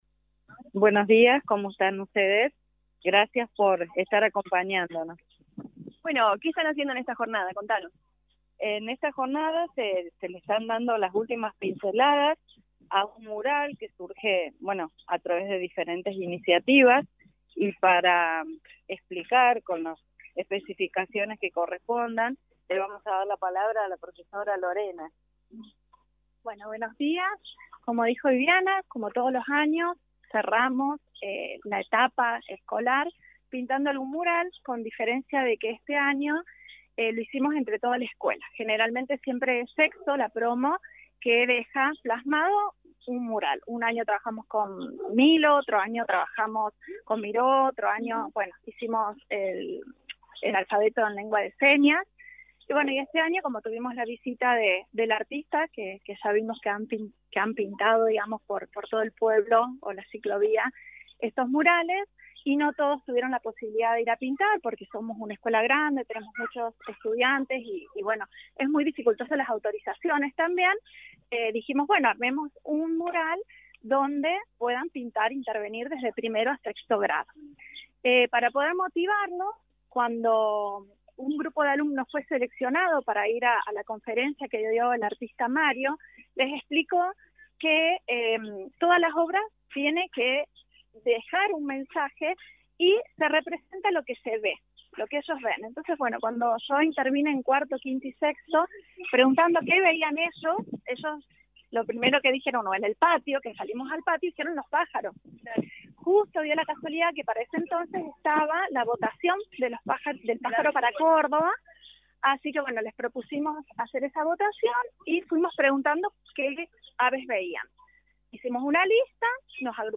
En diálogo con LA RADIO 102.9 FM las docentes informaron que los chicos se motivan mucho con este tipo de actividades que les permiten expresarse libremente en el arte.